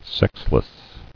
[sex·less]